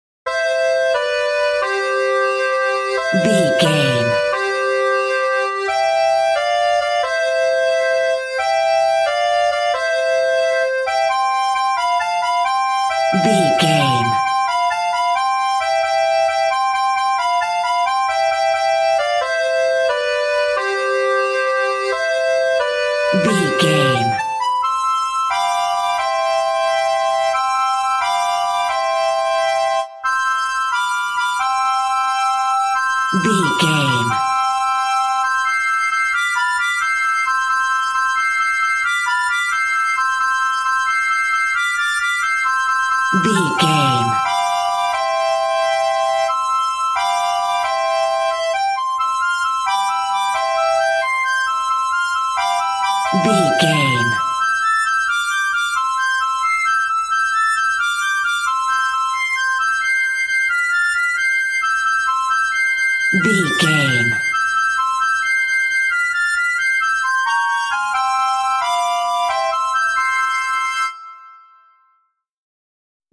Ionian/Major
nursery rhymes
childrens music